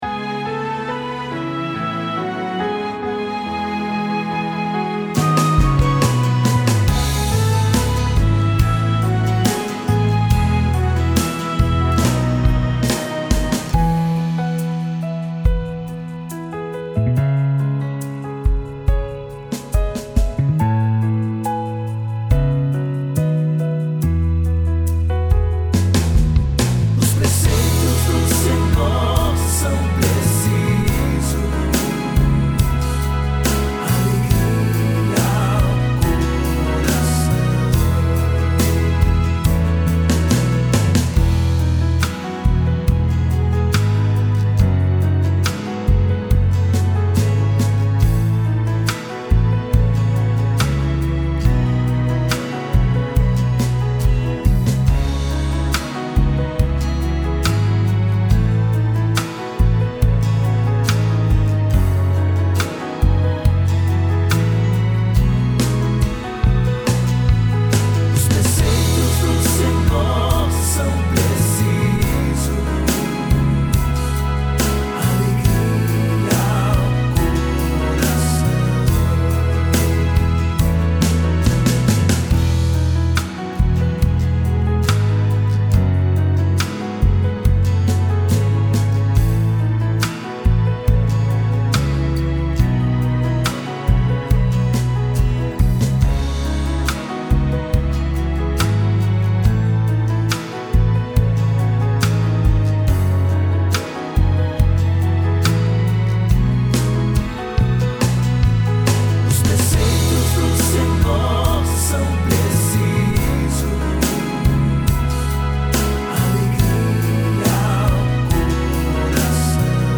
PlayBack (música)